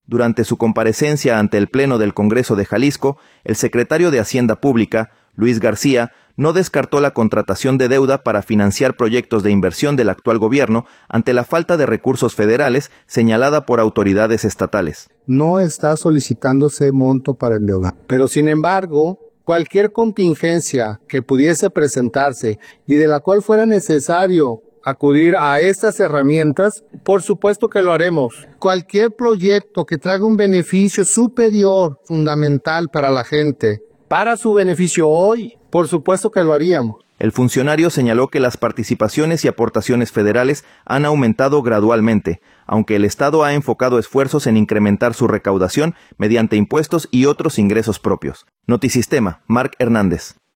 Durante su comparecencia ante el pleno del Congreso de Jalisco, el secretario de Hacienda Pública, Luis García, no descartó la contratación de deuda para financiar proyectos de inversión del actual gobierno, ante la falta de recursos federales señalada por autoridades estatales.